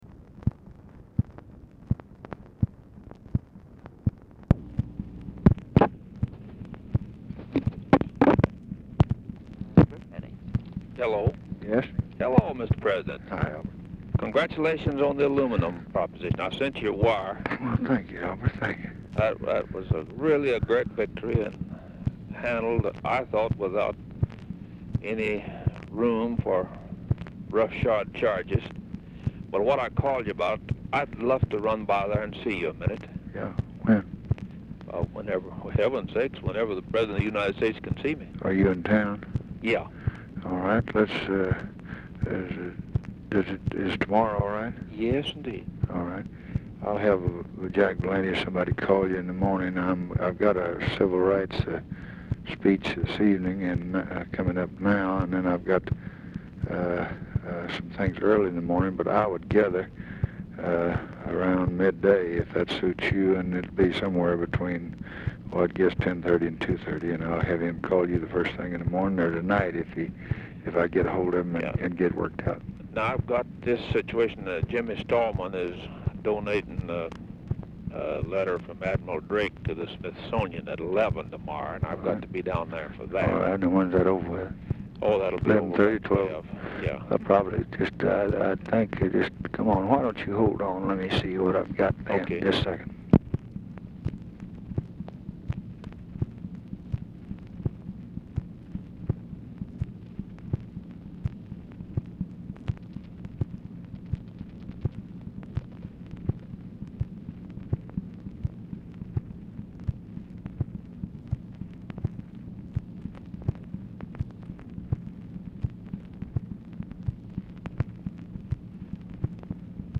Telephone conversation # 9172, sound recording, LBJ and ALBERT GORE, SR., 11/16/1965, 5:45PM | Discover LBJ
LBJ PUTS GORE ON HOLD BRIEFLY WHILE CHECKING HIS SCHEDULE
Dictation belt